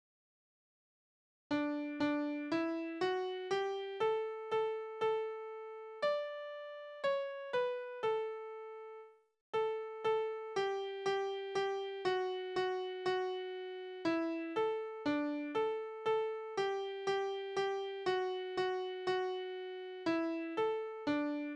Necklieder: Große Frau und kleiner Mann
Tonart: D-Dur
Taktart: 4/4
Tonumfang: Oktave
Besetzung: vokal